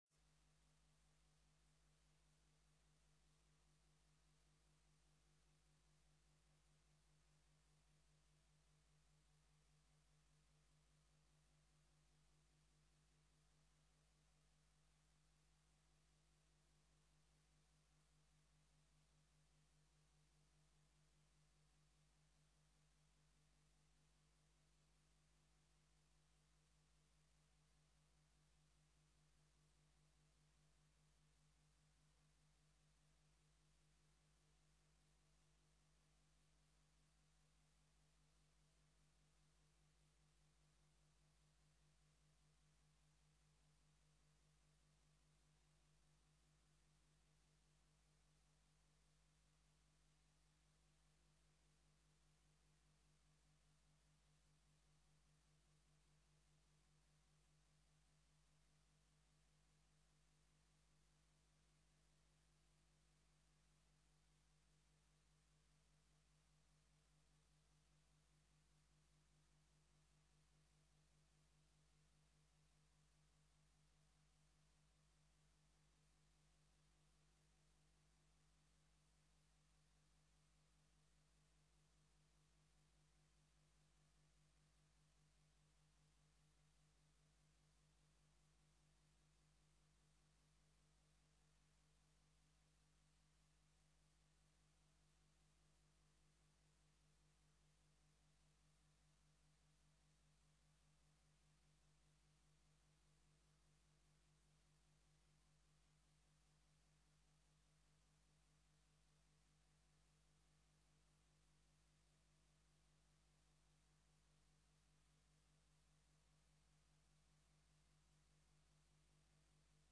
Download de volledige audio van deze vergadering
Locatie: Raadszaal